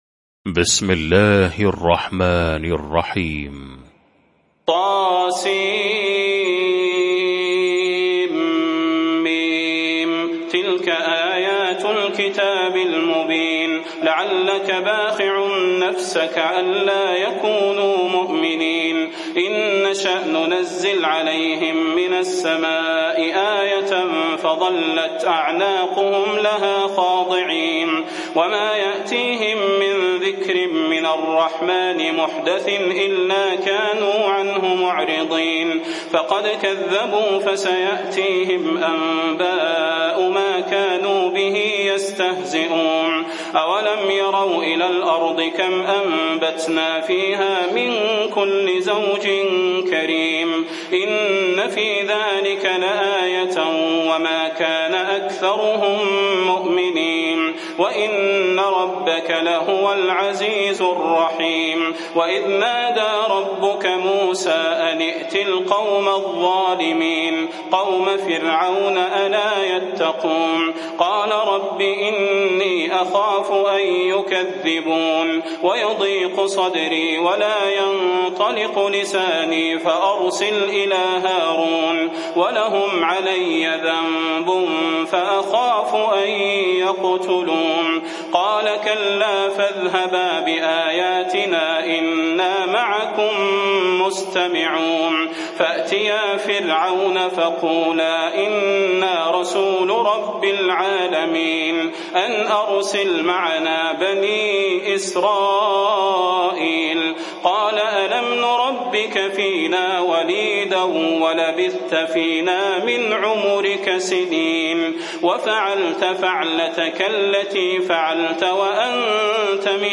المكان: المسجد النبوي الشيخ: فضيلة الشيخ د. صلاح بن محمد البدير فضيلة الشيخ د. صلاح بن محمد البدير الشعراء